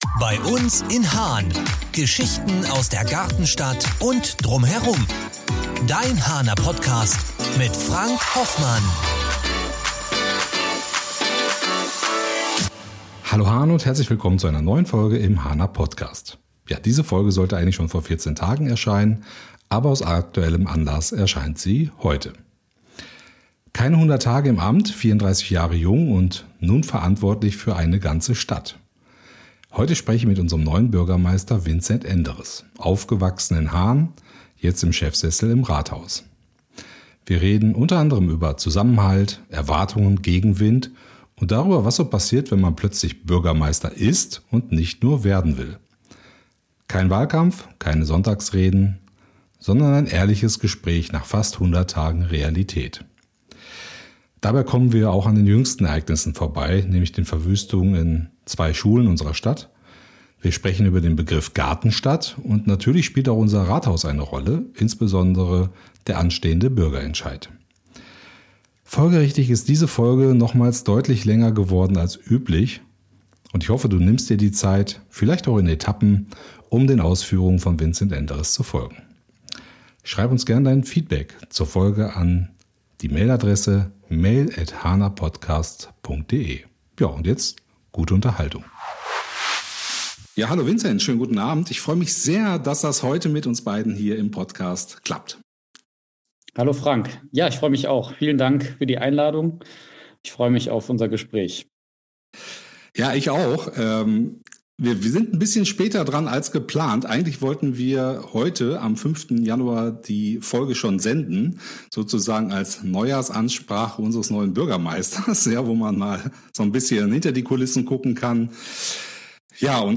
#95 Bürgermeister, Nachbar, Haaner: Ein Gespräch nach (knapp) 100 Tagen ~ Haaner Podcast